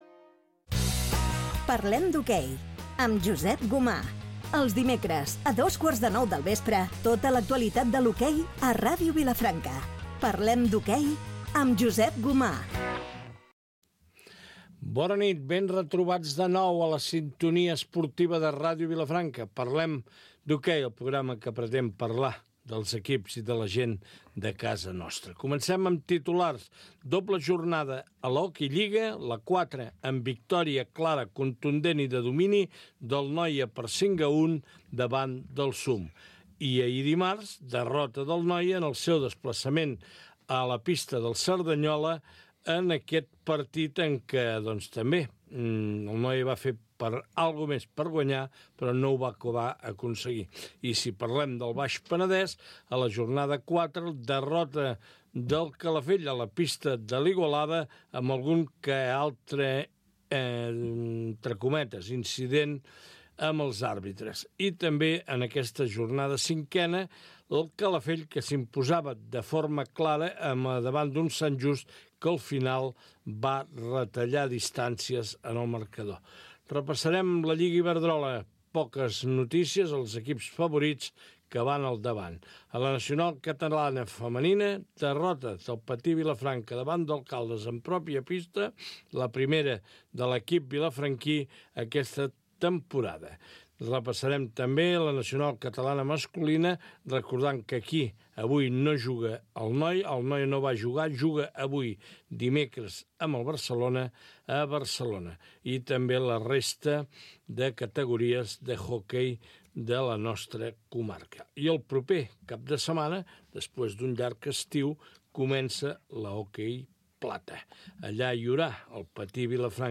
Especial final de temporada des del Casino